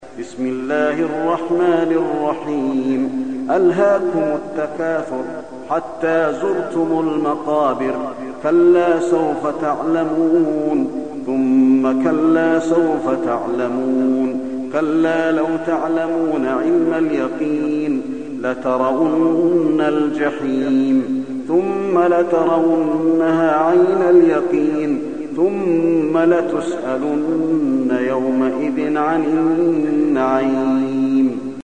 المكان: المسجد النبوي التكاثر